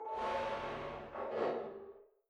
ajout du sfx de sortie du vaisseau
ship_exit.wav